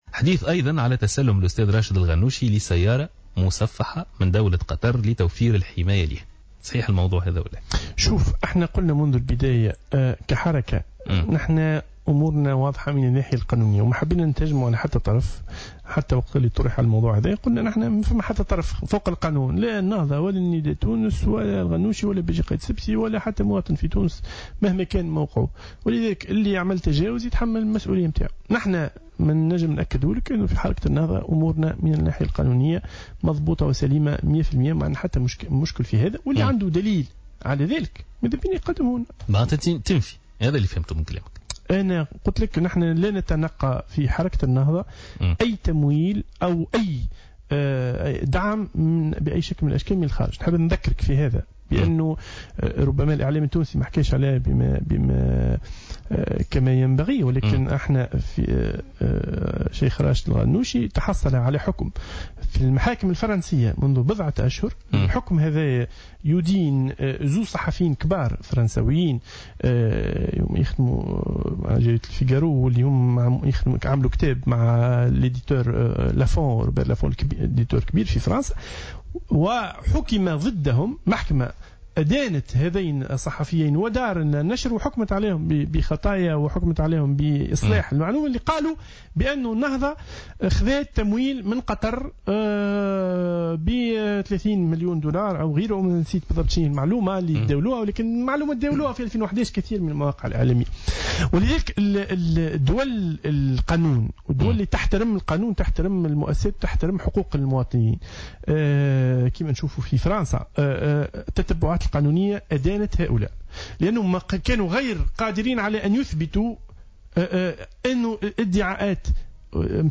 Dans une déclaration accordée à Jawhara Fm, le porte-parole d'Ennahdha, Zied Ladhari, a nié que le président du mouvement Rached Ghannouchi ait reçu une voiture anti-balle du Qatar, assurant que le mouvement nahdhaoui respecte la loi des partis.